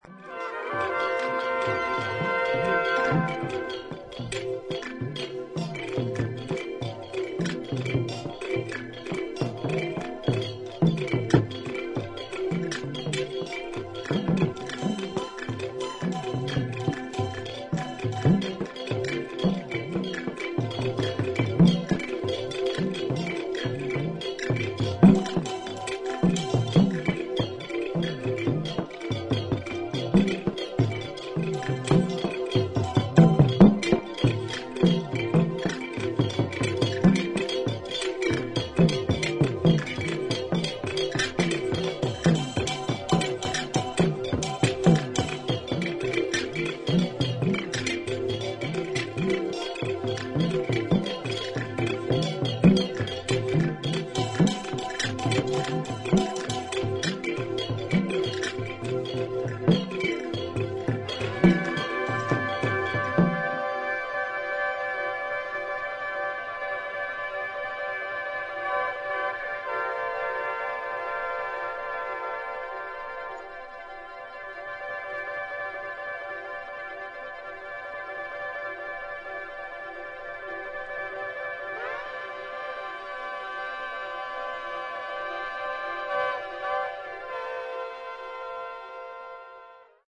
土着的なアフリカものからニューウェーブ、ダブ、ロック、現代音楽までジャンルをクロスオーバーした良作が多数収録